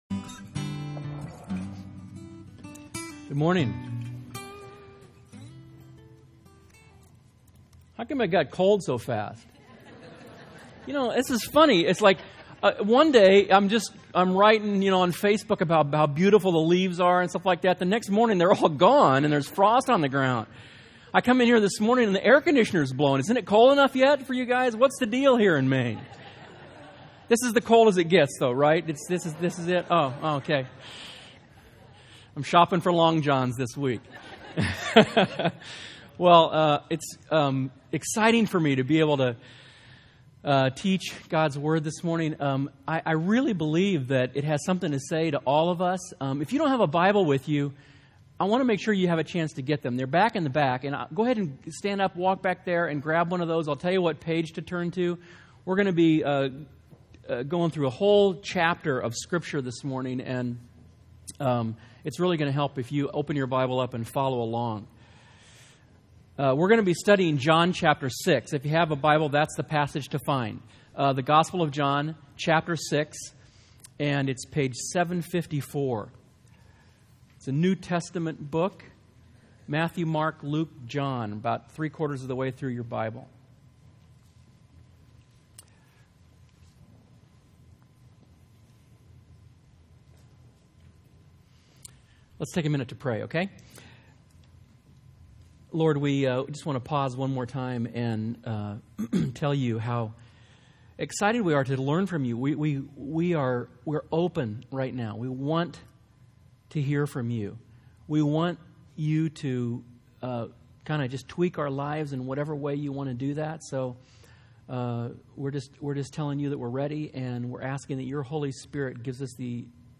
as presented at White Pine Community Church October 24th